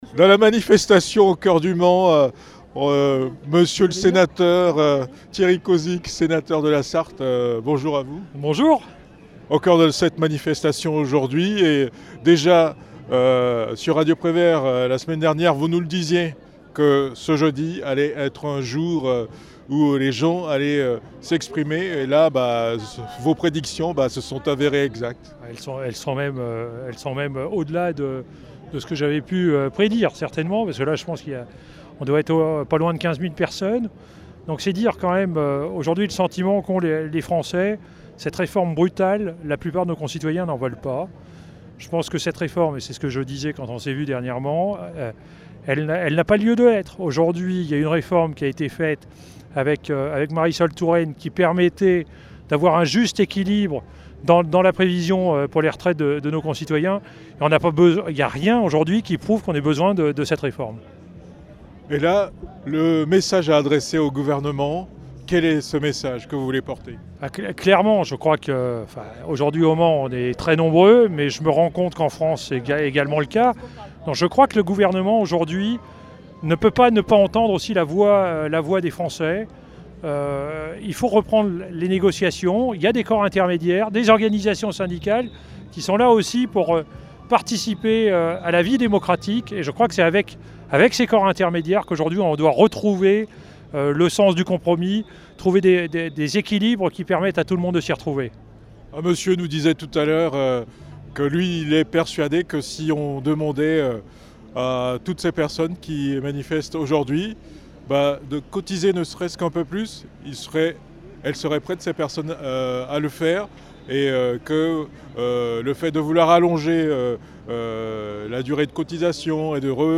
Manifestation contre la réforme des retraites au Mans